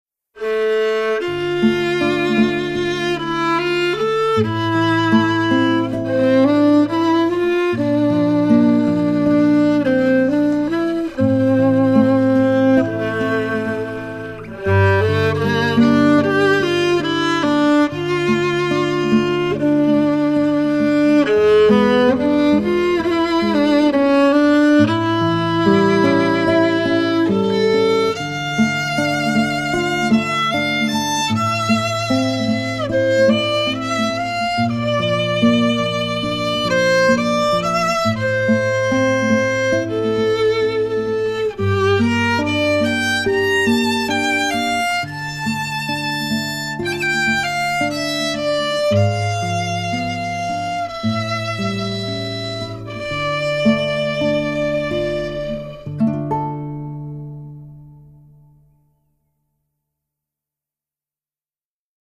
Allegro [150-160] amour - violon - triste - doux - ancien